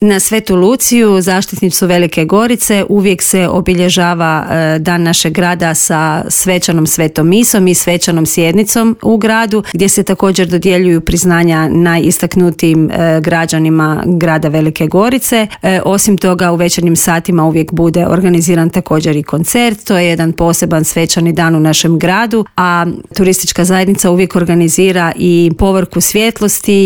Advent u Gorici najavila je u Intervjuu Media servisa